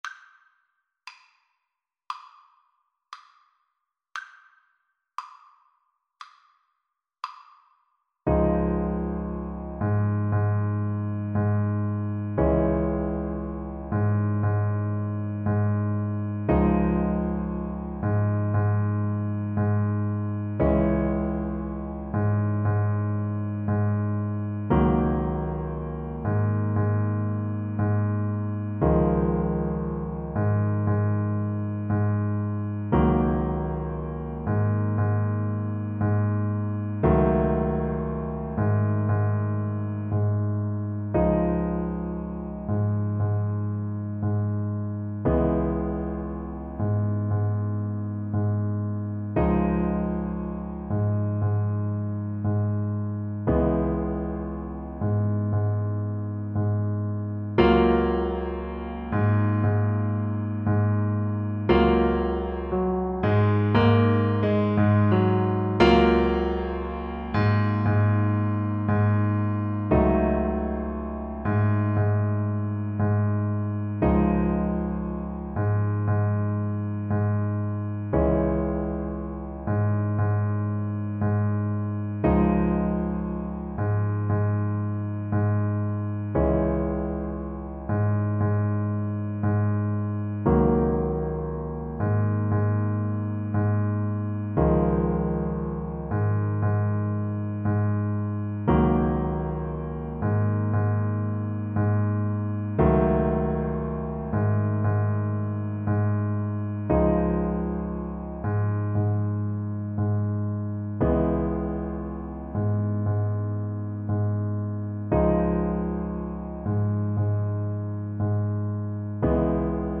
Alto Saxophone
A relaxed melody with a Rumba beat.
Andante
World (View more World Saxophone Music)